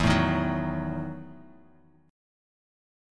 EM7sus2sus4 Chord
Listen to EM7sus2sus4 strummed